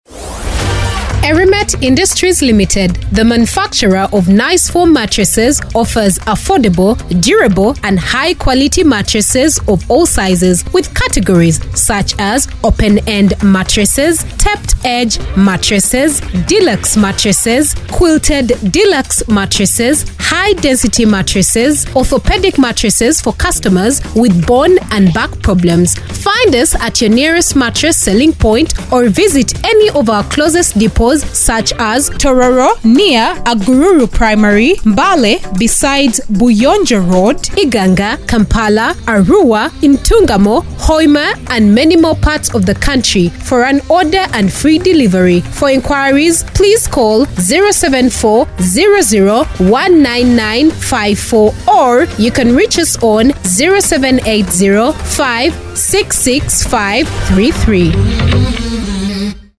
NICEFOAM ADVERT
nicefoam-advert.mp3